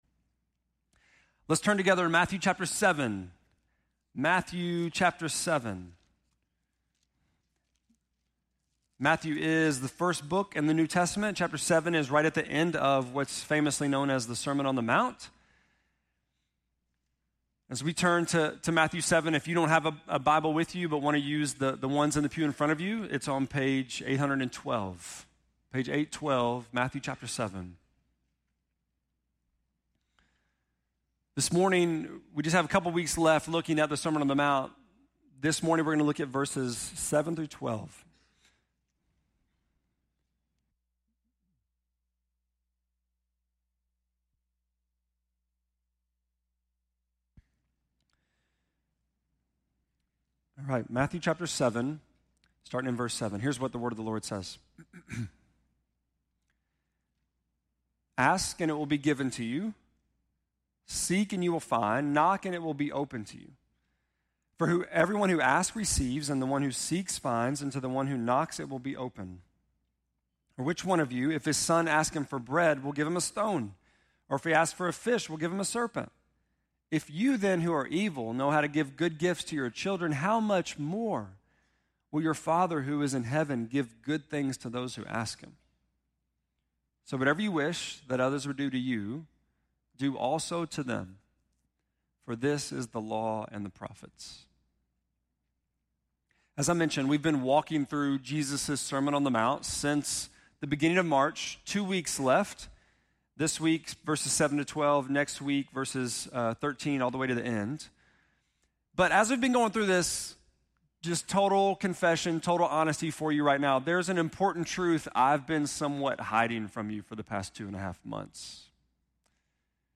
5.19-sermon.mp3